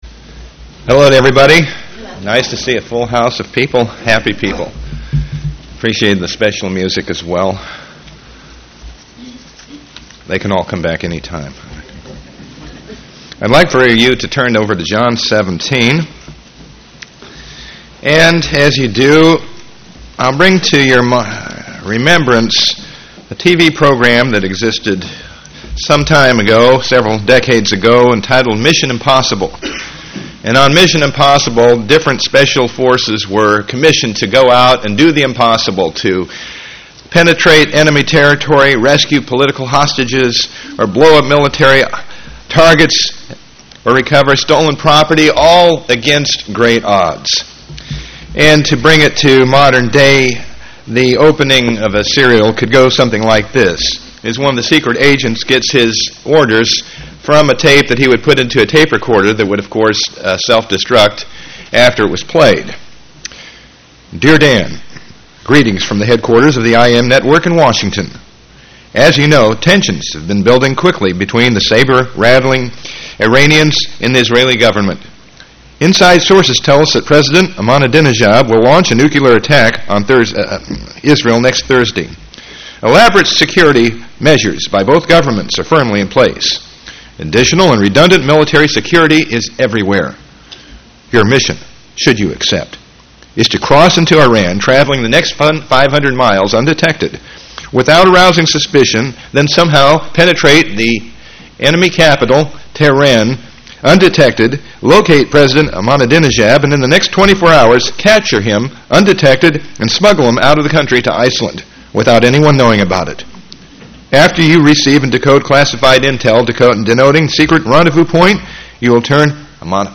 Using a “Mission Impossible” theme, this message shows the apparently impossible missions given to the apostles and to us. Without God's Spirit to help us, they are impossible; however, once we receive God’s Spirit at baptism, all things become possible. This message was given on the Feast of Pentecost.
UCG Sermon Studying the bible?